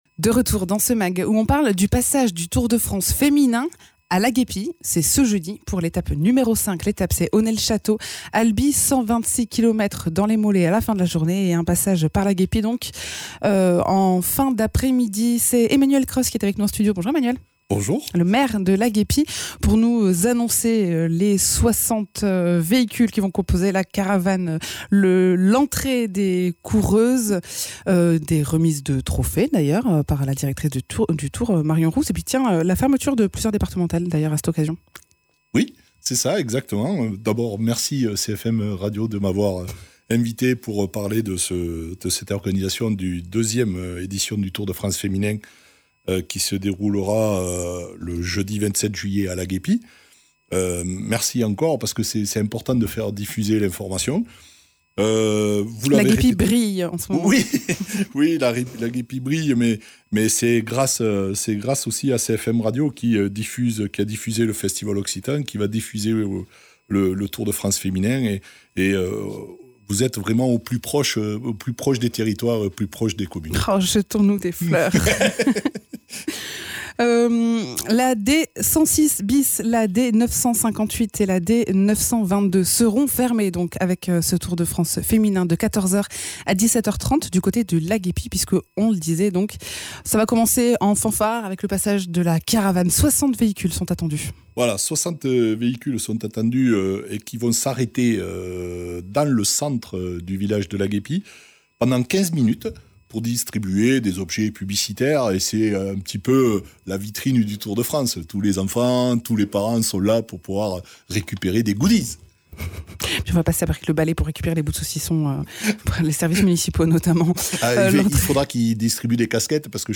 Interviews
Invité(s) : Emmanuel Cros, maire de Laguepie